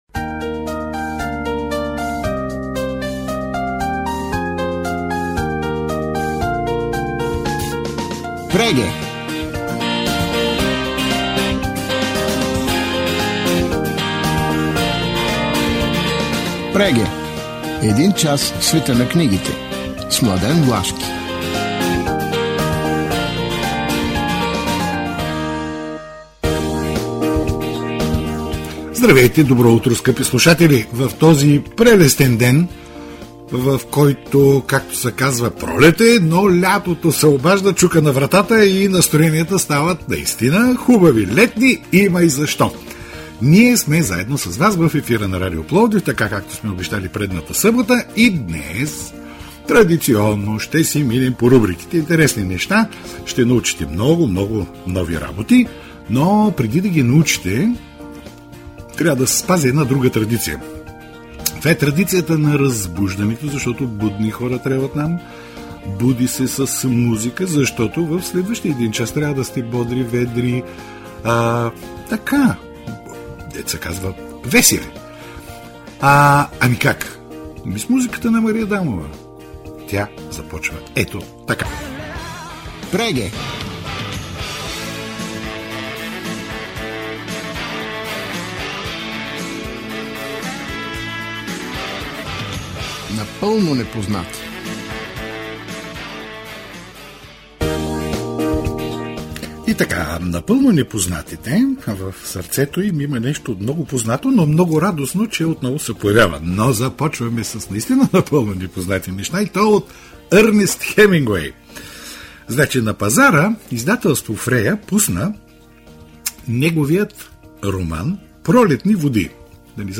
В Преге – предаването за книги на Радио Пловдив, на 3 май 2025 бяха представени следните заглавия: